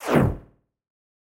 motor_swing_down.wav